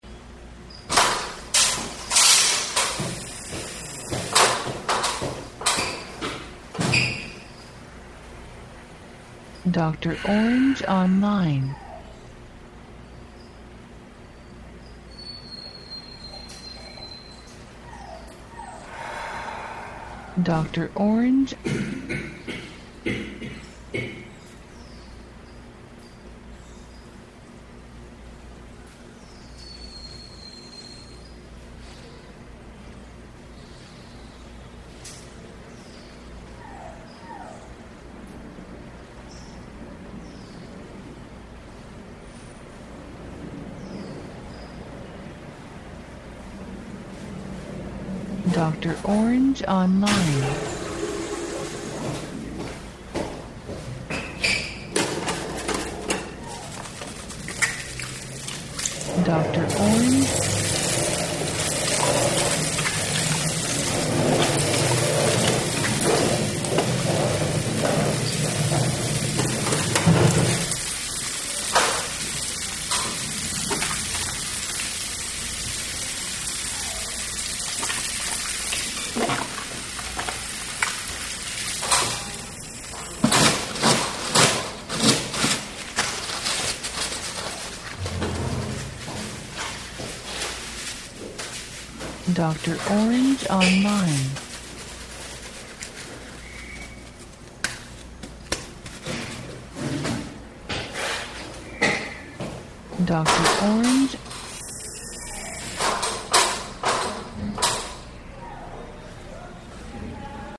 Ambiente Ambisónico Interior Baño Público Persona interactuando
INTERIOR BAÑO PUBLICO, PERSONA INTERACTUANDO Y CAMINANDO; LAVADO DE MANOS, SECADO DE MANOS.
Archivo de audio AMBISONICO, 96Khz – 24 Bits, WAV.